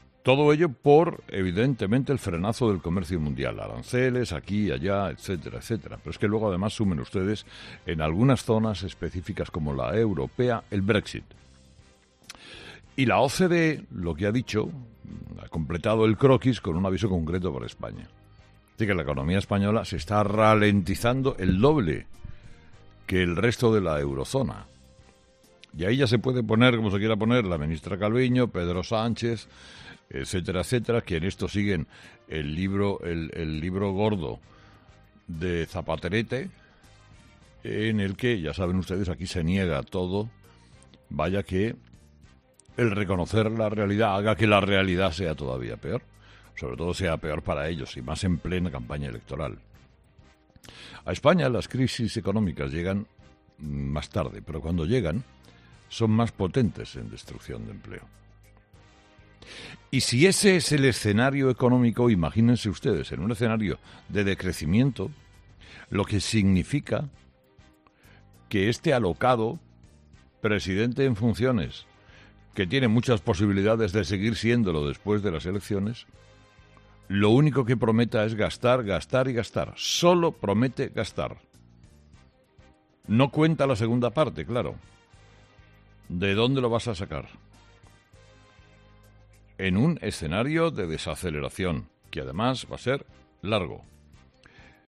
El comunicador ha criticado con dureza las promesas que está realizando el presidente en funciones en una situación de desaceleración económica